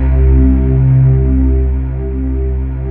Index of /90_sSampleCDs/USB Soundscan vol.28 - Choir Acoustic & Synth [AKAI] 1CD/Partition D/24-THYLIVOX